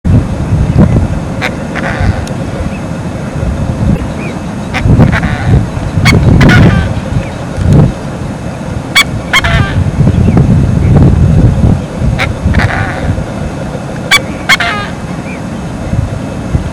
Cisne coscoroba, Cisne blanco
El nombre coscoroba es una onomatopeya del graznido de la hembra, generalmente de alerta.